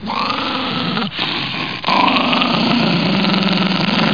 1 channel
doggrowl.mp3